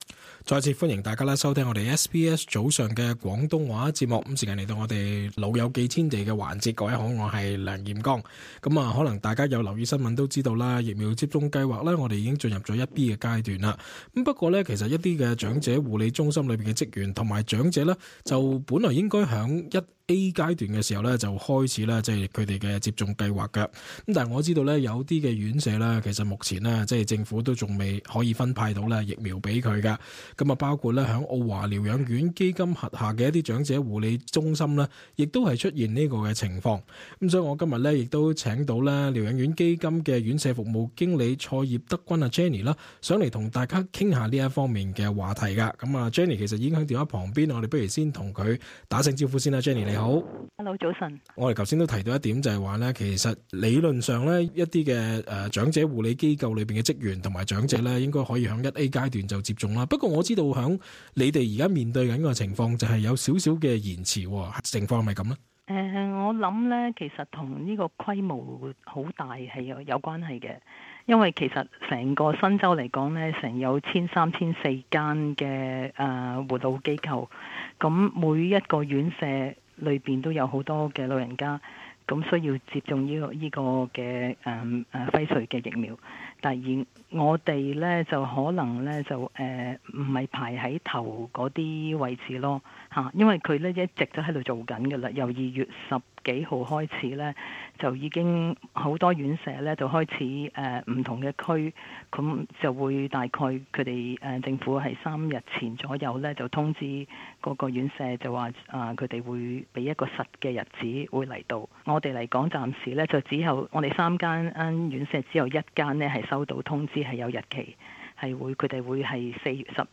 talkback-covid_vaccine_in_aged_care_home_0.mp3